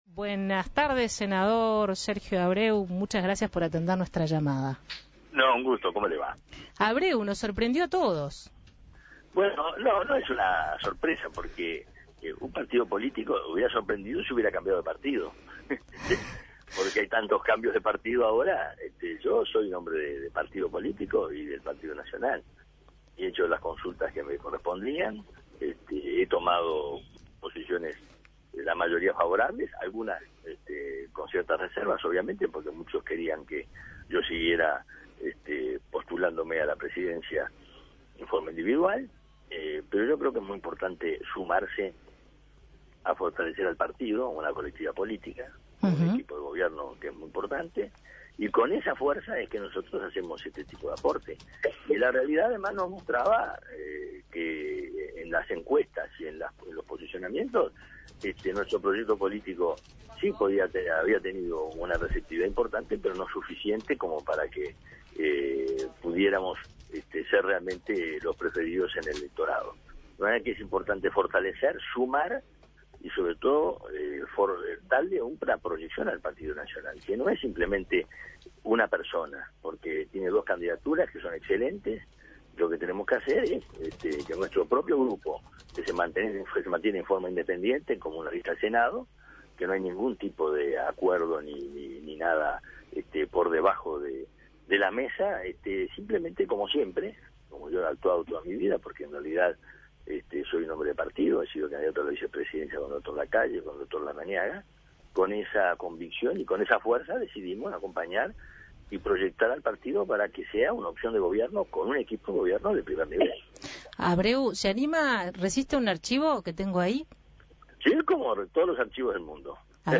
El senador Sergio Abreu se bajó de la precandidatura a la Presidencia de la República por el Partido Nacional y se sumará al grupo de Luis Lacalle Pou. En diálogo con El Espectador el líder del sector Dignidad Nacional dijo que la decisión "no es una sorpresa".